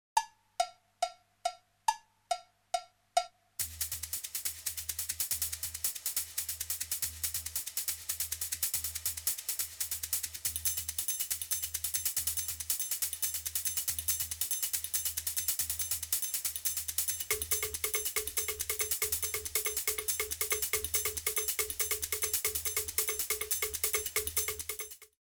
15 tracks of original percussion music
Nice consistent long tracks  - world music style